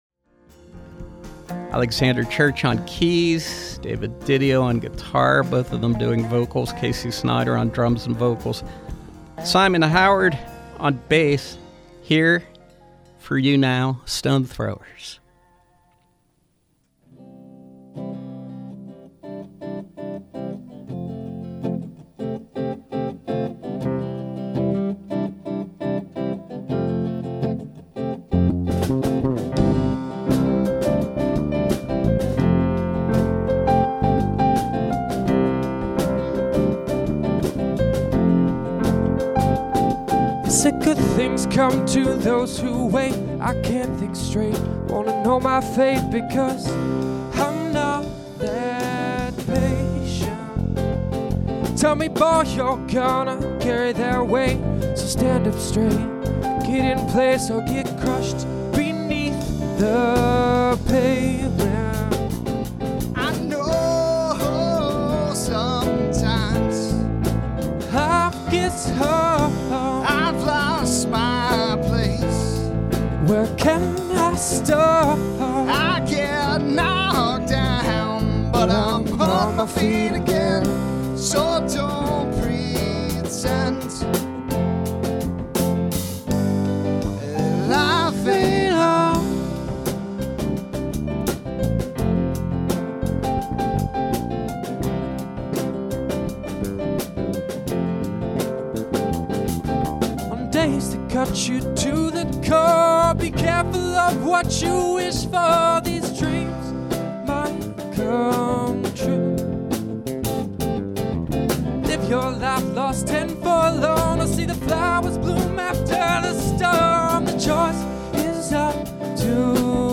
Groove-Americana